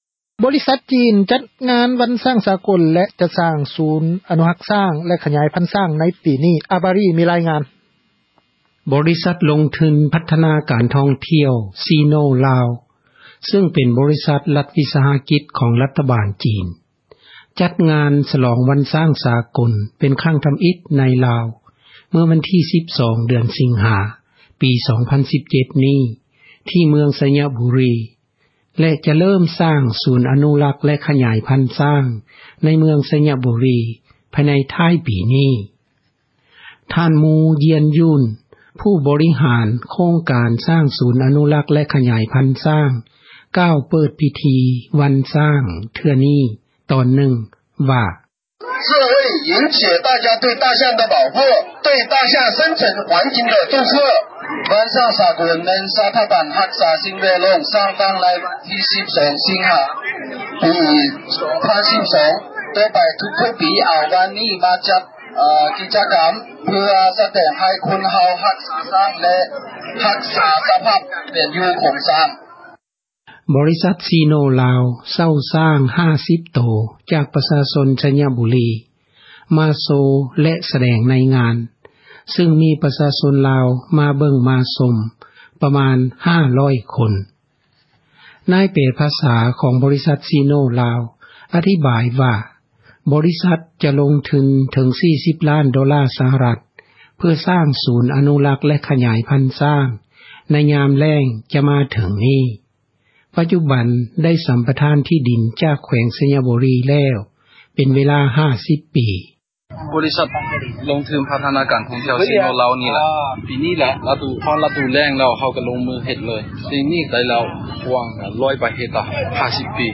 ສ່ວນເຈົ້າໜ້າທີ່ ແຂວງໄຊຍະບຸຣີ ທ່ານນຶ່ງ ຢືນຢັນວ່າ ບໍຣິສັດຈີນ ດັ່ງກ່າວ ຈະສ້າງສູນຊ້າງ ໃຫ້ເປັນແຫລ່ງທ່ອງທ່ຽວ ໃນເມືອງໂຊຍະບຸຣີ:
ຊາວເມືອງໄຊຍະບຸຣີ ຜູ້ນຶ່ງ ທີ່ມາຮ່ວມງານຊ້າງ ສາກົນເທື່ອນີ້ ໃຫ້ຄຳຄິດຄຳເຫັນ ກ່ຽວກັບ ໂຄງການ ຂອງຈີນວ່າ: